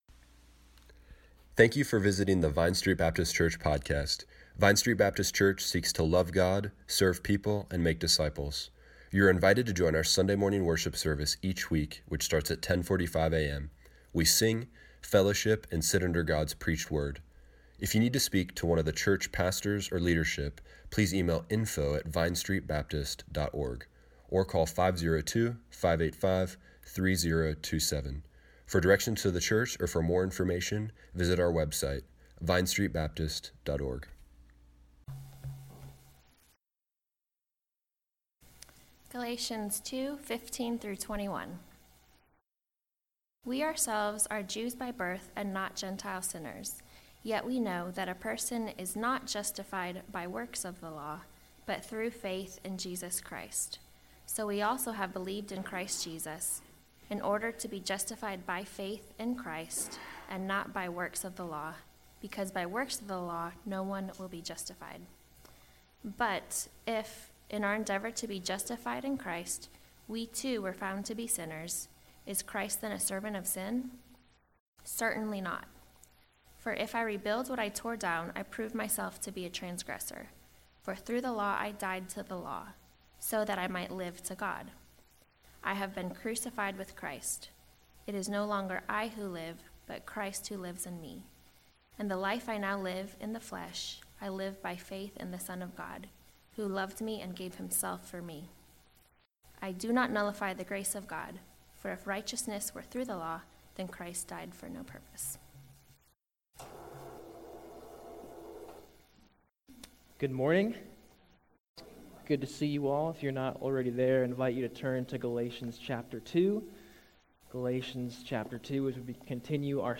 Morning Worship